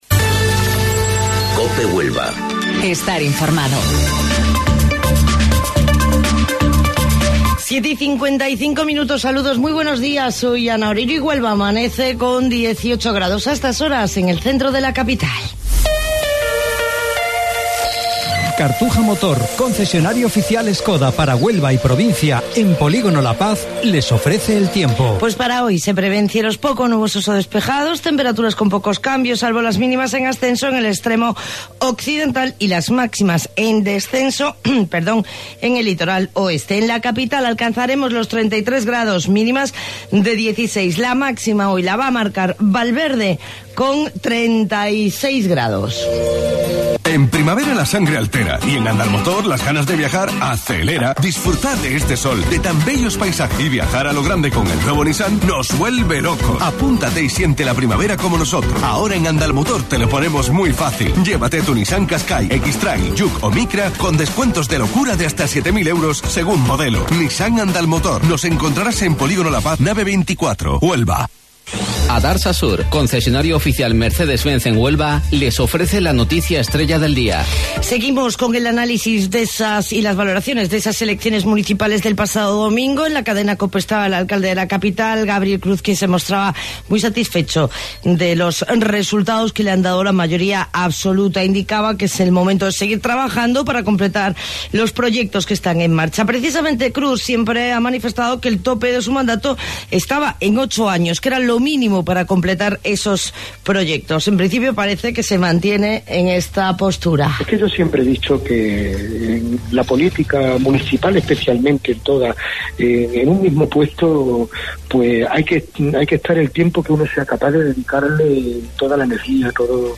AUDIO: Informativo Local 07:55 del 29 de Mayo